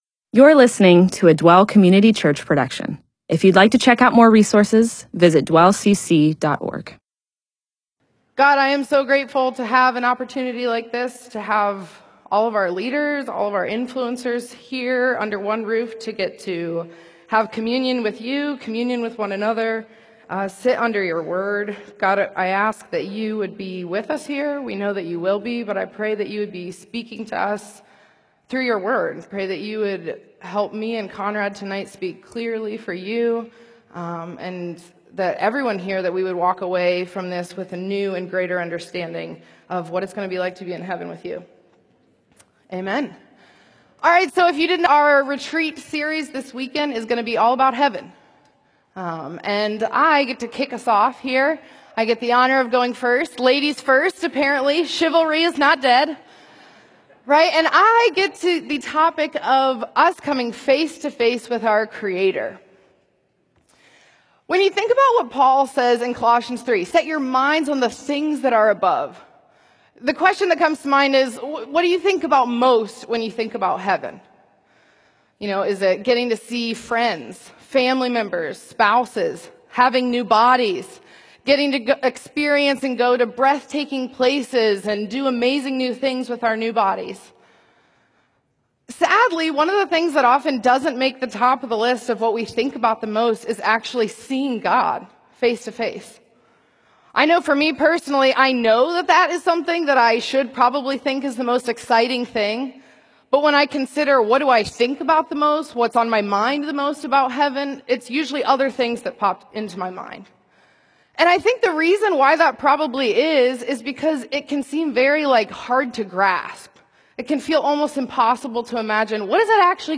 MP4/M4A audio recording of a Bible teaching/sermon/presentation about Revelation 22:4-5.